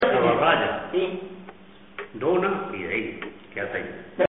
Materia / geográfico / evento: Refranes y proverbios Icono con lupa